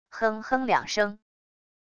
哼哼两声wav音频